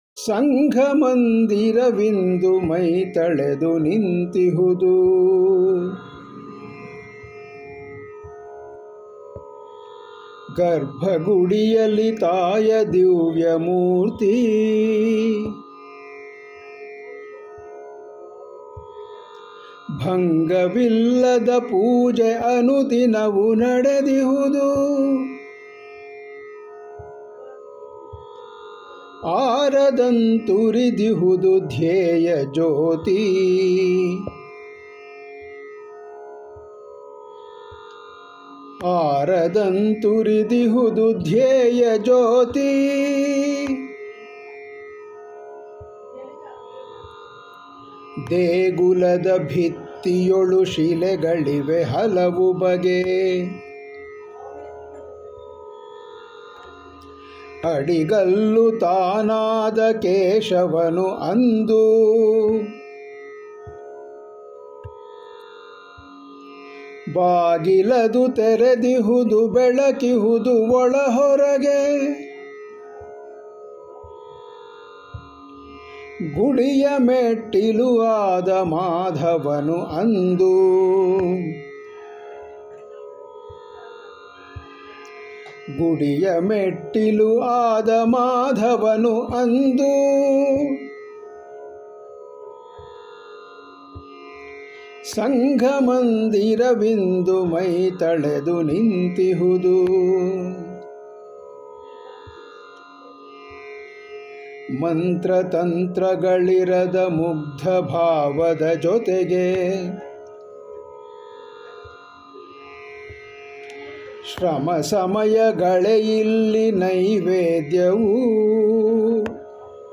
Patriotic Songs Collections